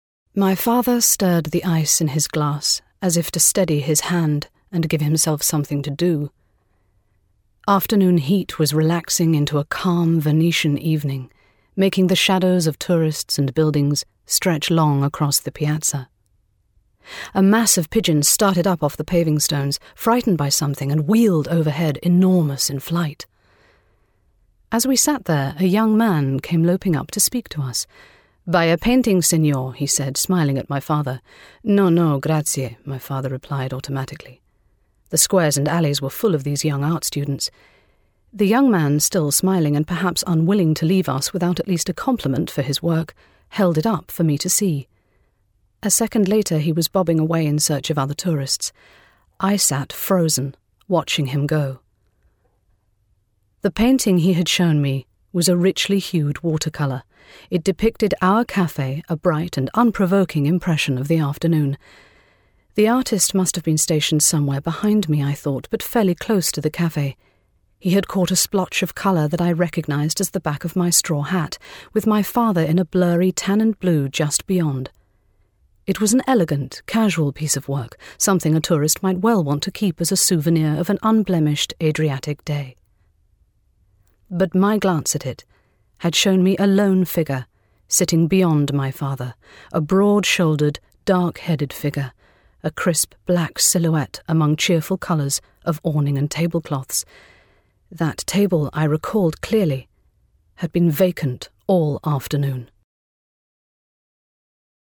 • Native Accent: RP
• Home Studio